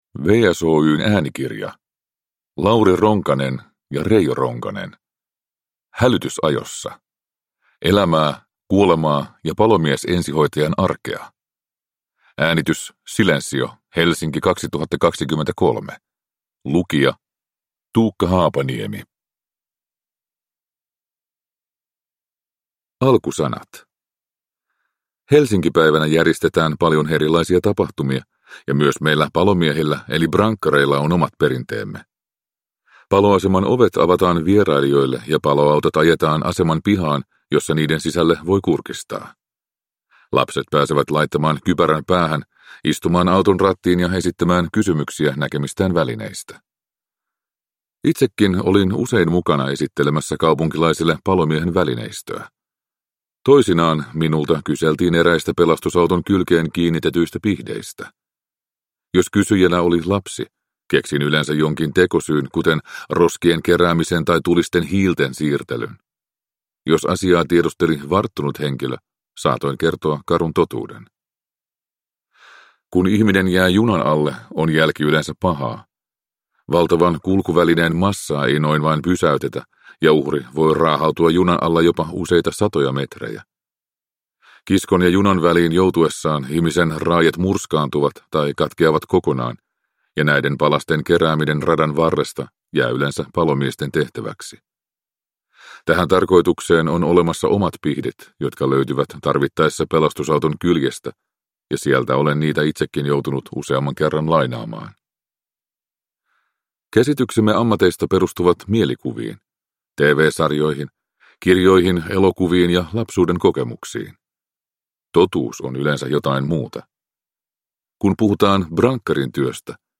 Hälytysajossa – Ljudbok – Laddas ner